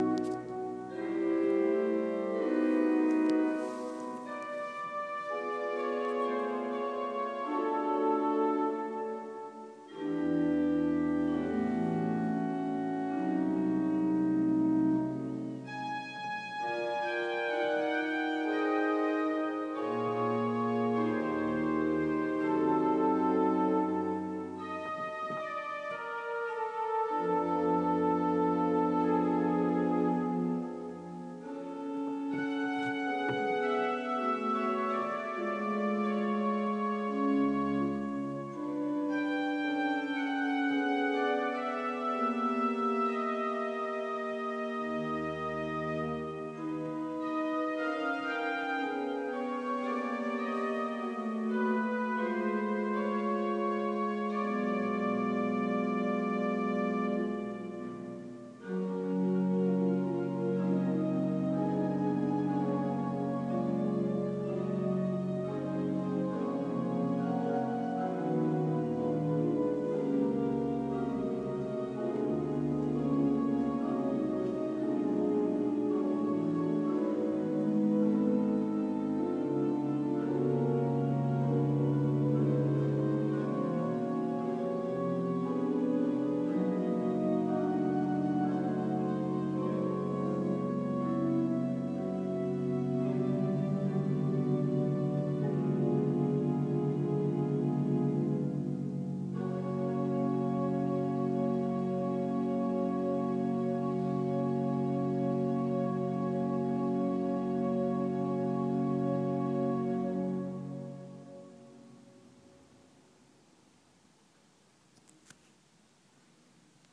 St. Wenzel, Naumburg, Orgelkonzert, nichtBach,meereswelle, music, wunderschön